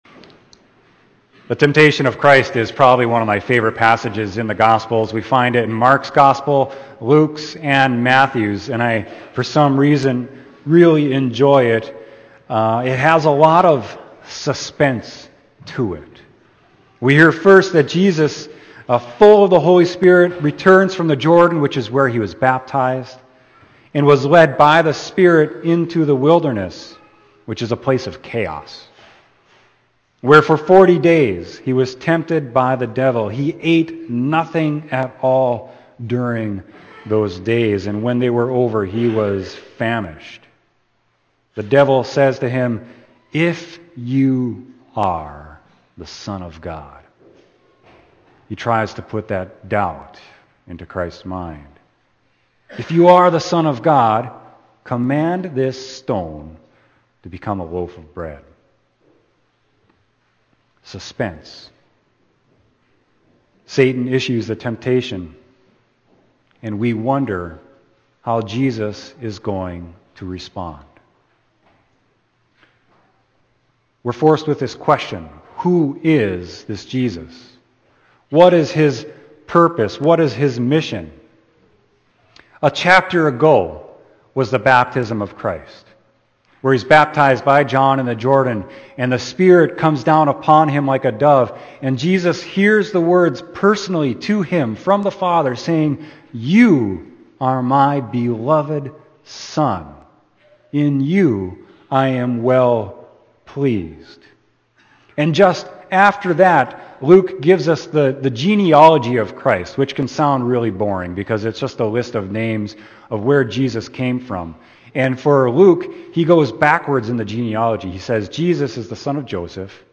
Sermon: Luke 4.1-13